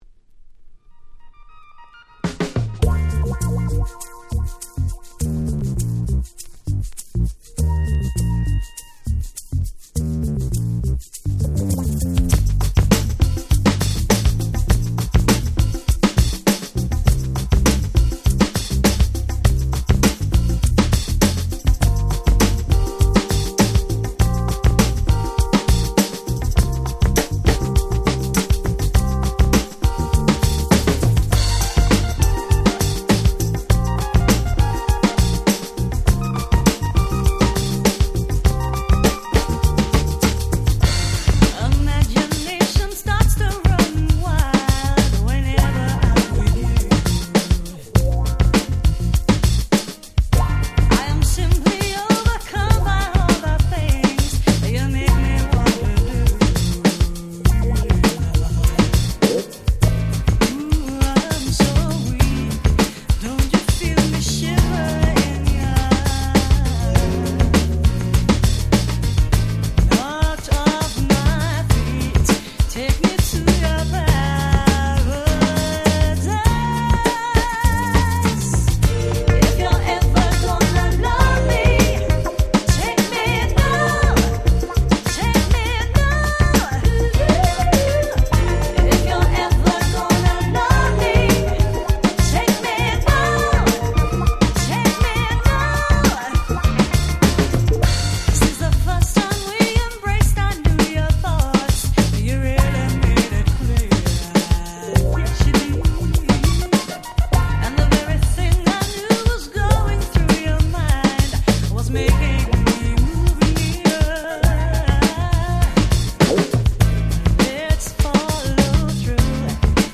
91’ Very Nice Acid Jazz/UK Street Soul !!
爽快感に溢れるサウンドは海沿いの通りを愛車で走りながら聴きたくなる感満載！
BeatもしっかりしていてDJでも使い易いです！
コチラのRemixはオリジナルよりGround Beat感強めで大人な雰囲気！